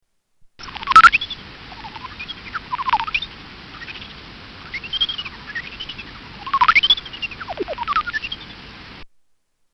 Horseshoe bat echolocation
Category: Animals/Nature   Right: Personal